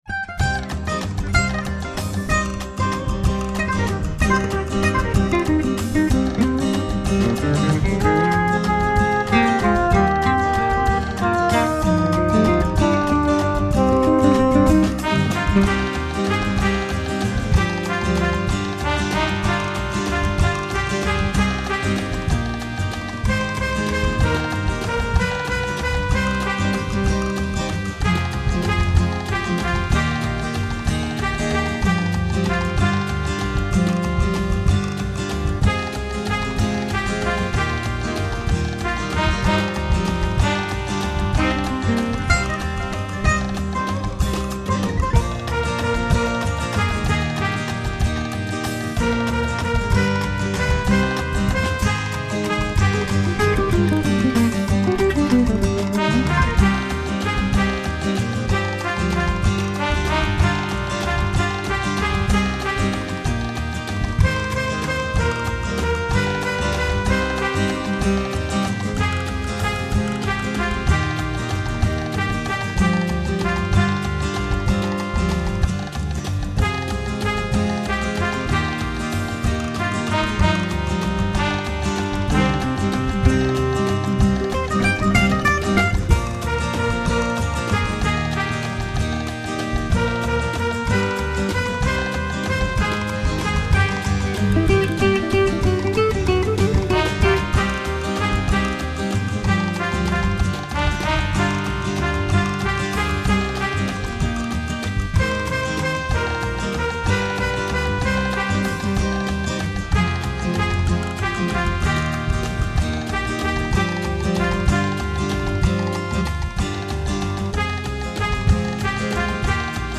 My backing is BIAB at its most crudely Spanish.